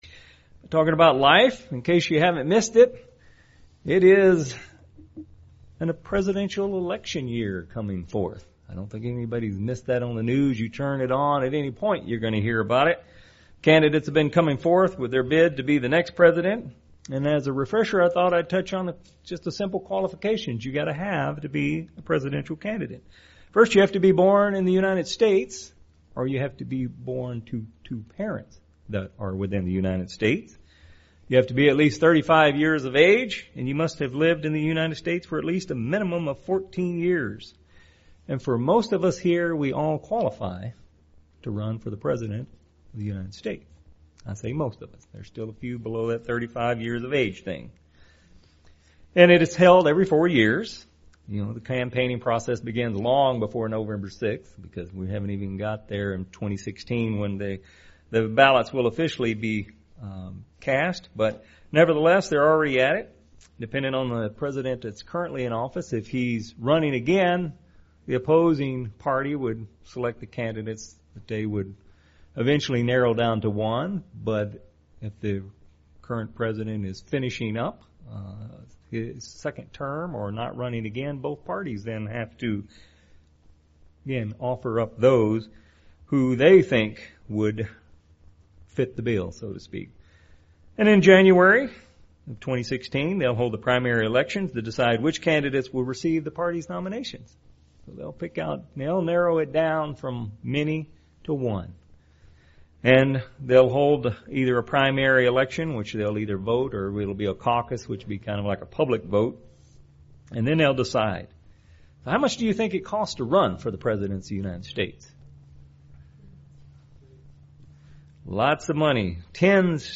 Given in Medford, OR
UCG Sermon Studying the bible?